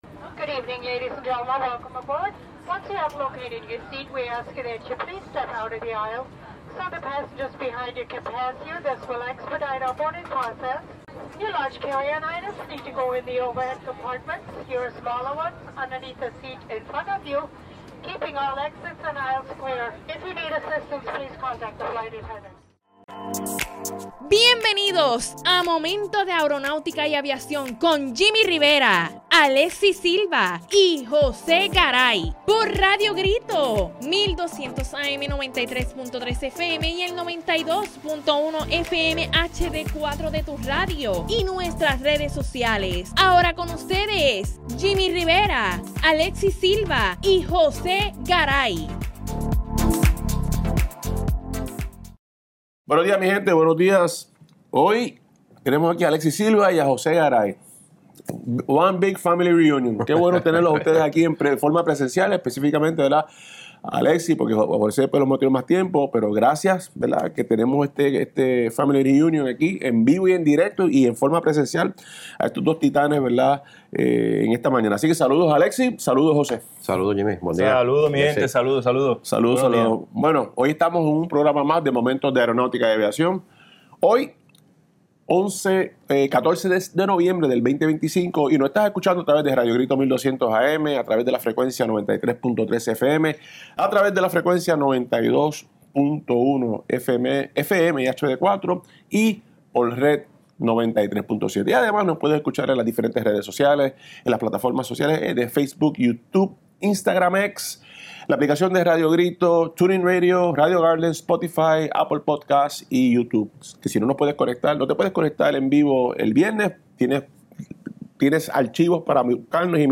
Y además del cierre vamos a tocar algunos temas de interés debido a varios sucesos acontecidos durante estas últimas dos semanas Panelistas: